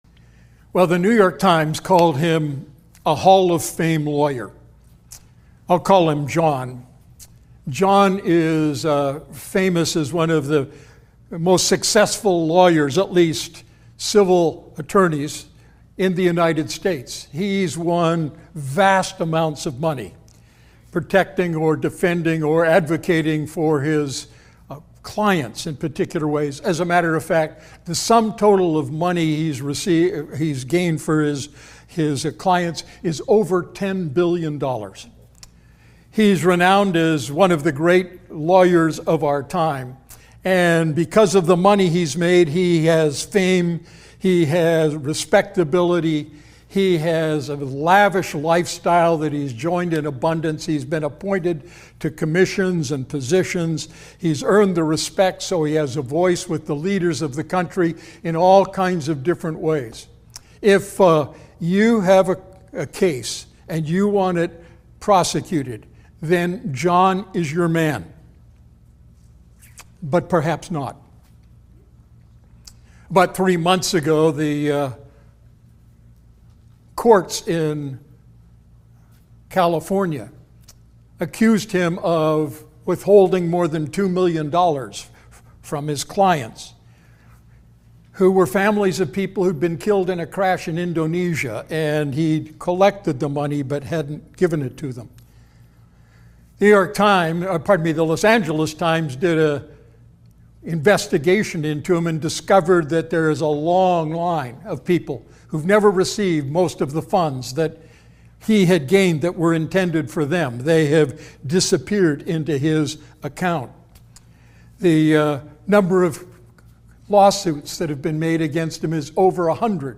Sermon Archive, Redeemer Fellowship